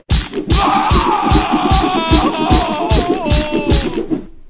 Added a sword combat soundpack for bashit.
reward.ogg